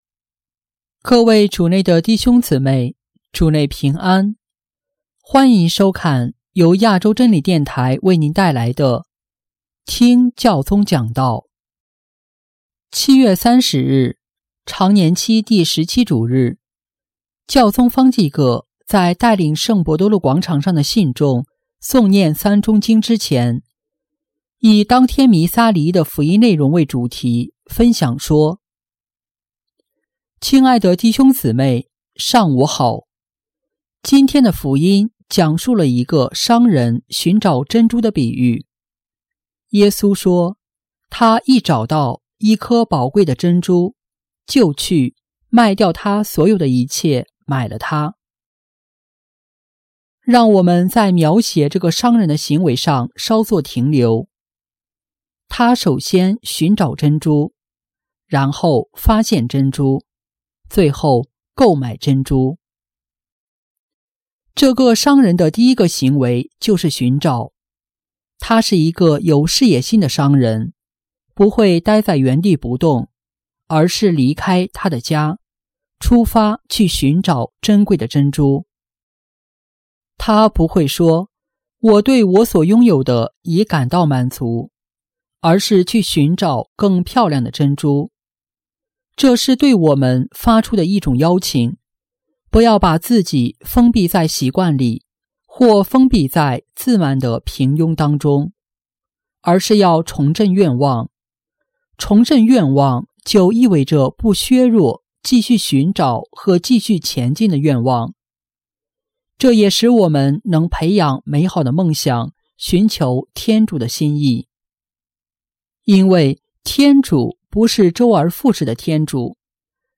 【听教宗讲道】| 寻找，找到和购买；耶稣值得我们投资
7月30日，常年期第十七主日，教宗方济各在带领圣伯多禄广场上的信众诵念《三钟经》之前，以当天弥撒礼仪的福音内容为主题，分享说：